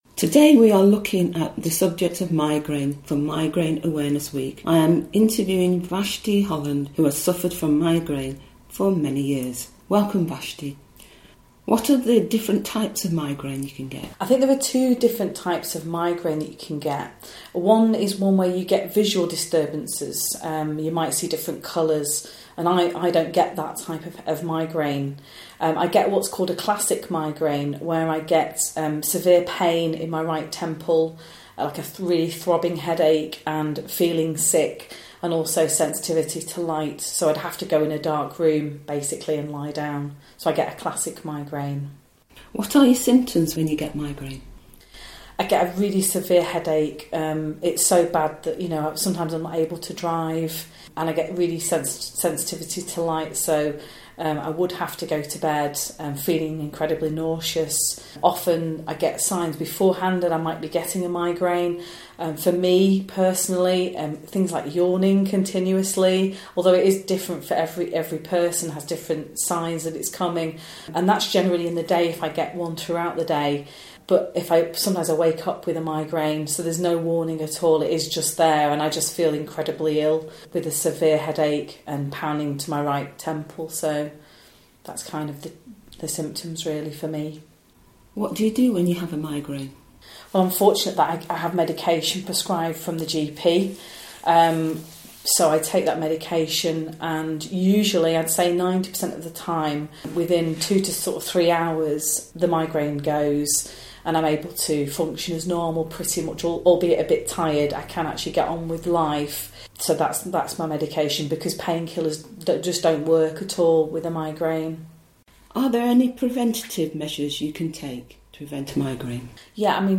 Migrane Interview no.1
A chat with someone who has experienced migraines for a number of years. Talking about symptoms and how this person is able to live with it.